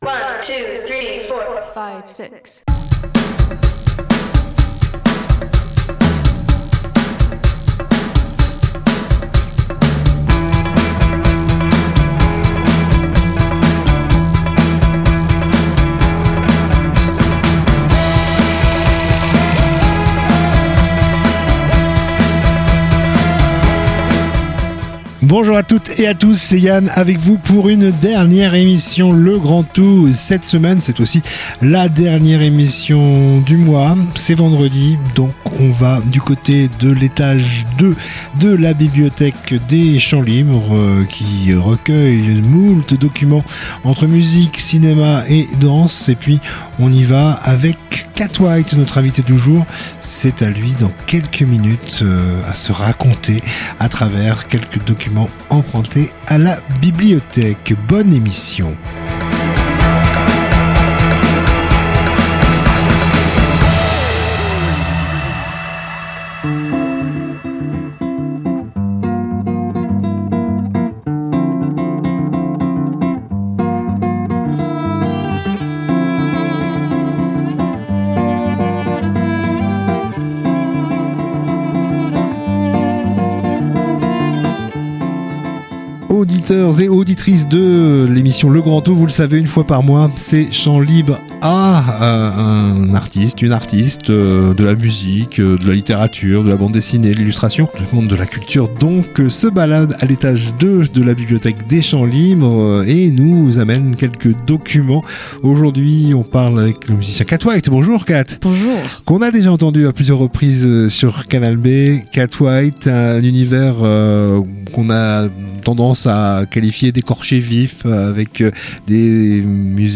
Et si vous deviez vous raconter à partir de documents empruntés à la bibliothèque ? C'est le défi que proposent Canal B et les Champs Libres à des personnalités du monde de la culture : chorégraphe, musicien, illustrateur, photographe…